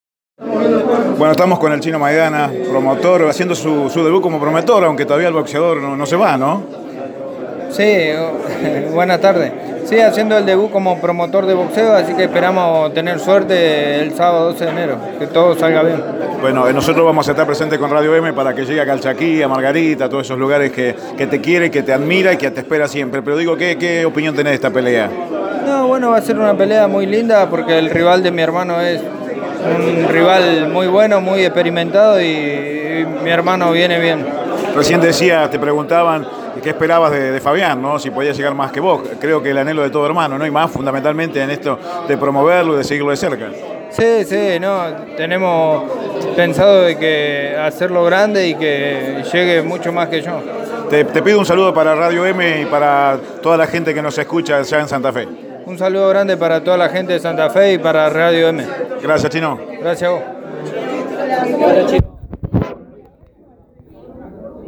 El Chino, en exclusiva con Radio EME